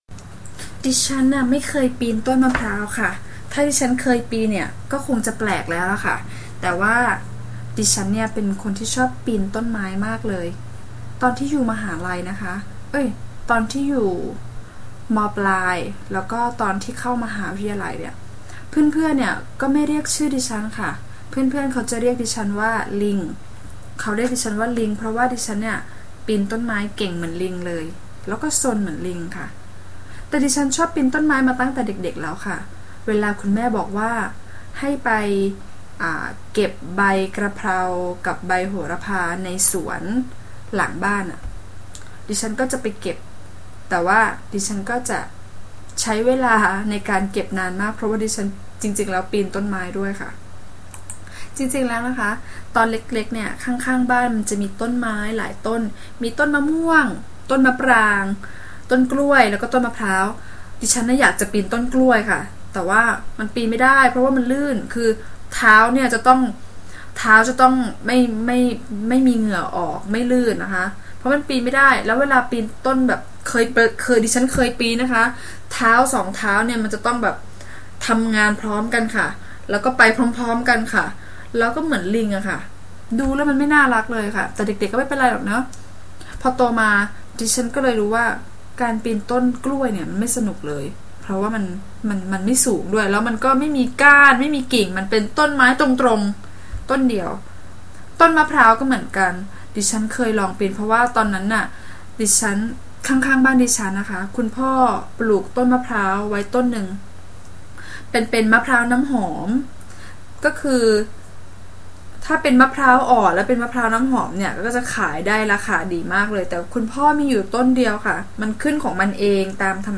All recordings are unscripted, natural speech and 100% in Thai; they all come with a transcript.
Native speakers